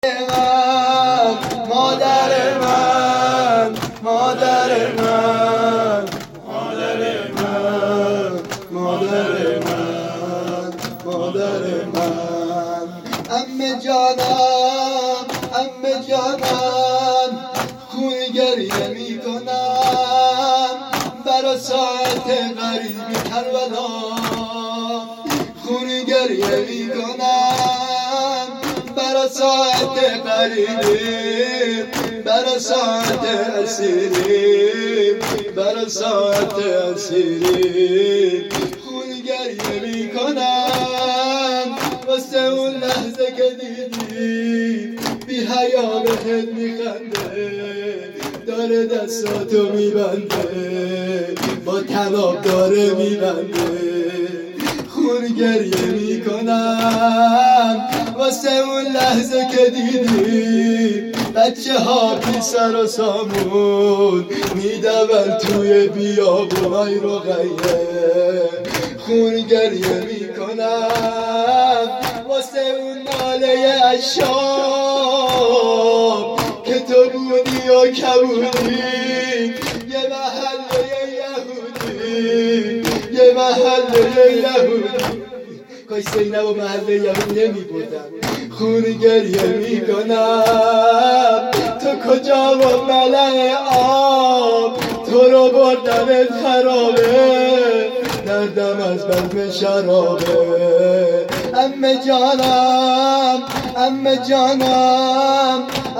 زمینه امون از شام